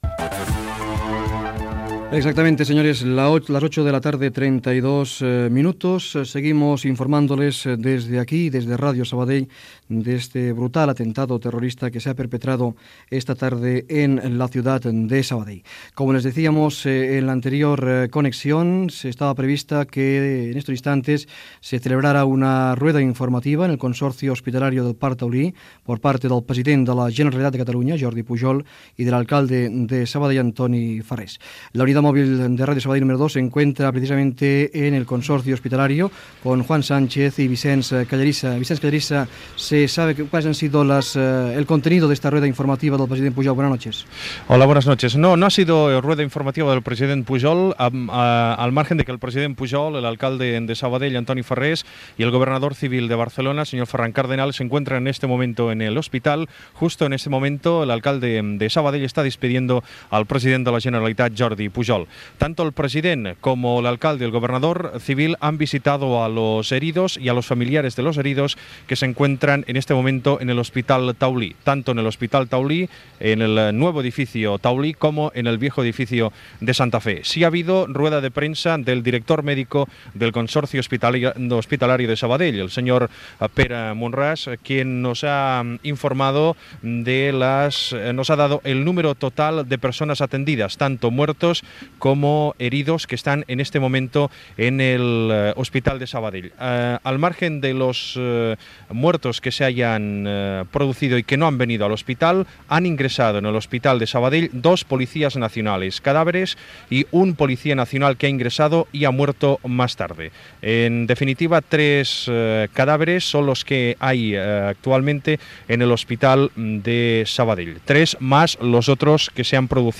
Informació sobre l'atemptat terrorista a Sabadell a una furgoneta de la Policia Nacional. Connexió amb la unitat mòbil per informar de l'estat mèdic dels ferits.
Informatiu